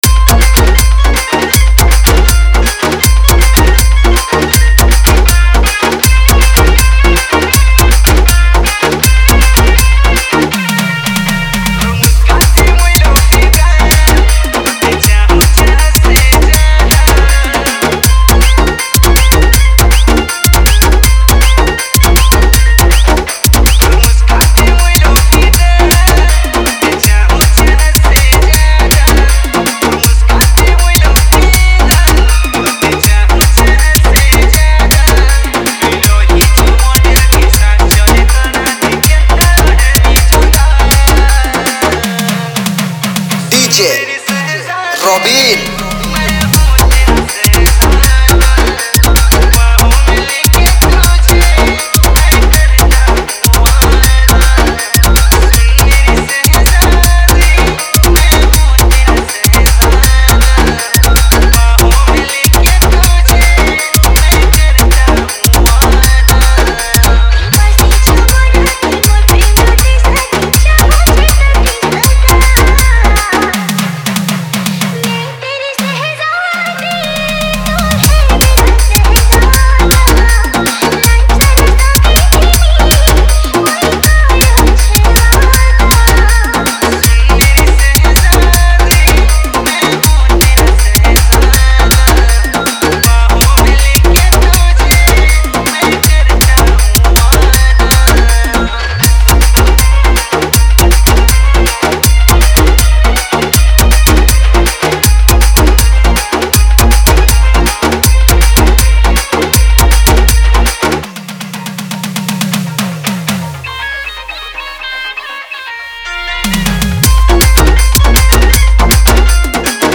Category: 2021 Holi Special Sambalpuri DJ Remix Songs